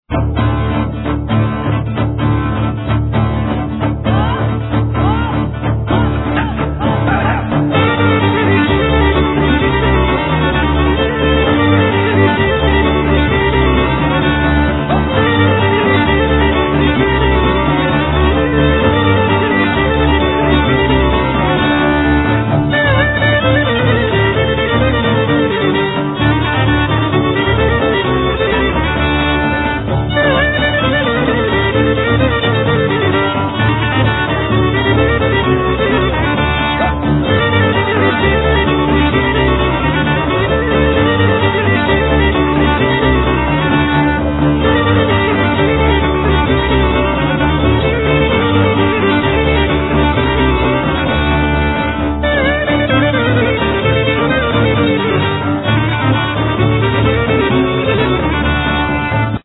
Tampoura, Gajda, Percussions, Chour, Vocal
Violin, Darbuka, Percussions, Choir, Vocal
5 strings fiddle, Percussions, Choir. Vocal
Davul, Darbuka, Pandeireta, Choir, Vocal
Double bass, Contras, Choir